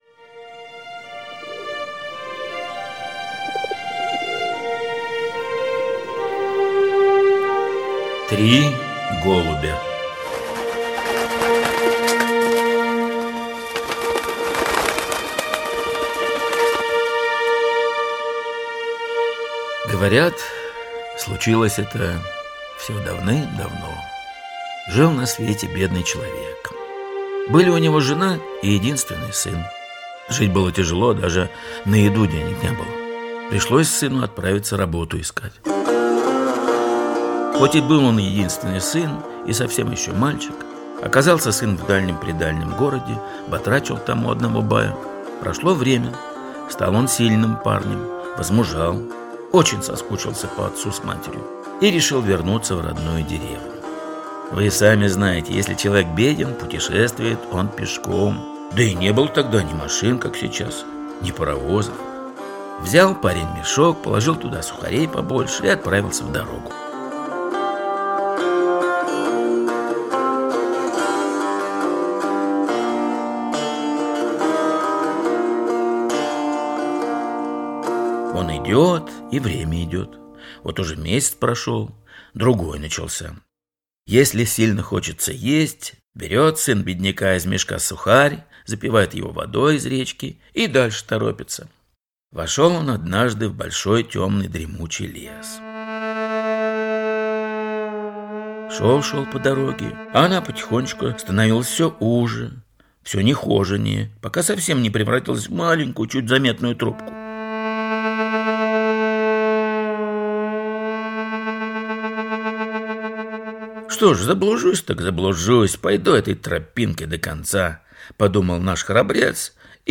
Три голубя - татарская аудиосказка - слушать онлайн